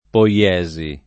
poiesi [ po L$@ i ] s. f.